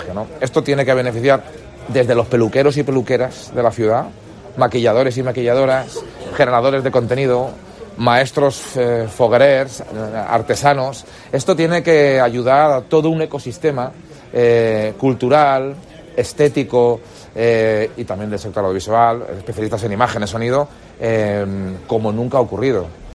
AUDIO: Escucha las declaraciones del President de la Generalitat Carlos Mazón